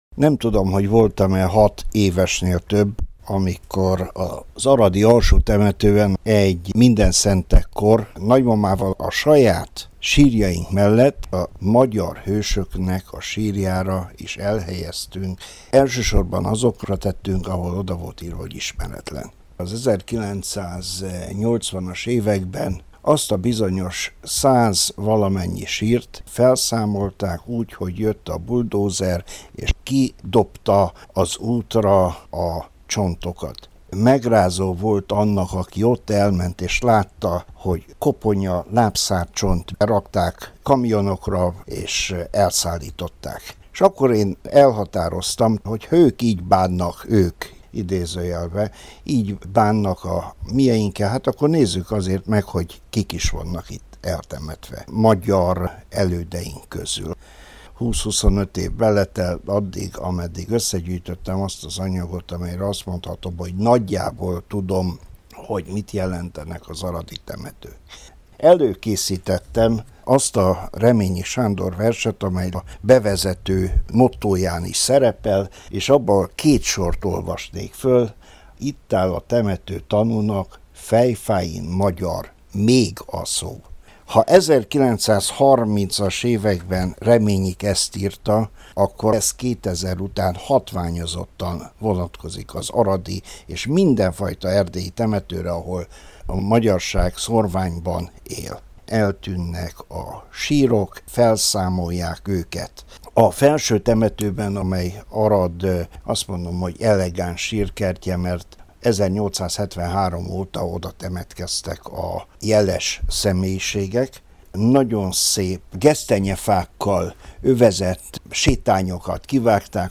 Hallgassa meg az interjút rádió archívumából!